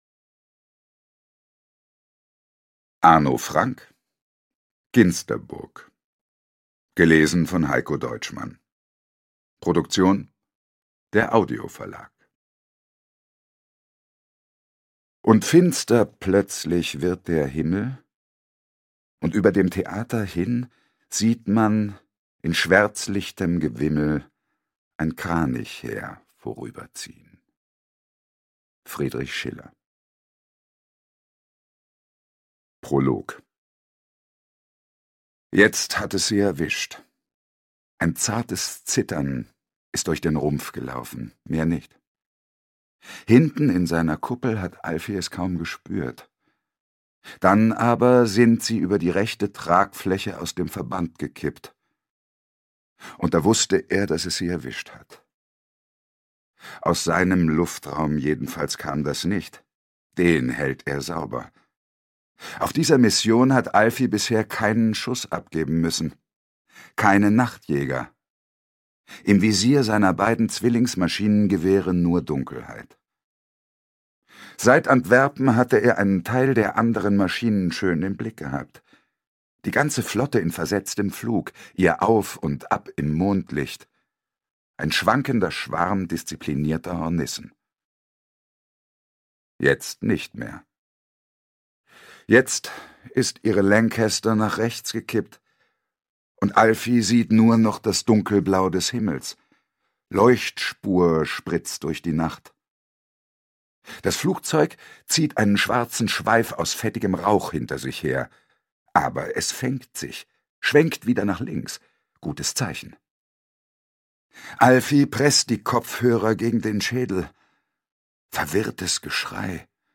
Heikko Deutschmann (Sprecher)
Die ungekürzte Lesung mit Heikko Deutschmann bei Der Audio Verlag
Mit seiner kraftvollen Stimme gelingt es ihm, die vielschichtigen Gefühle und inneren Spannungen der Charaktere authentisch zu transportieren.